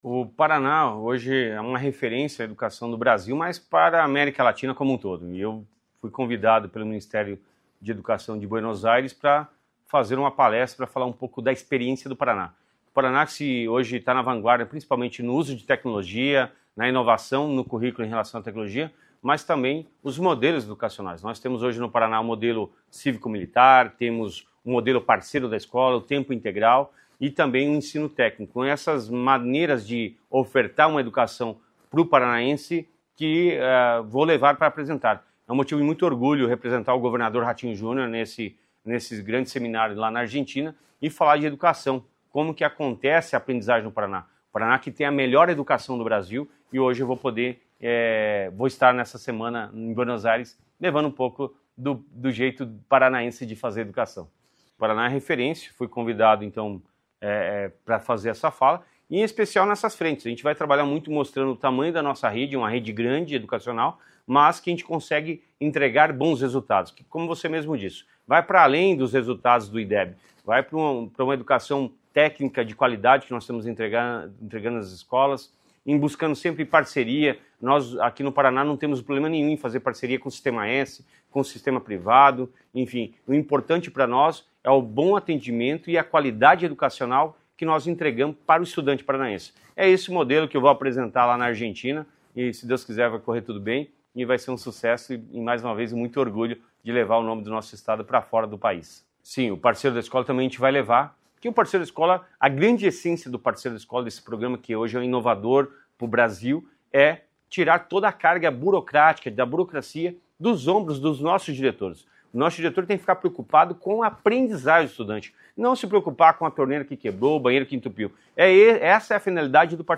Sonora do secretário da Educação, Roni Miranda, sobre a participação no Festival de Innovación Educativa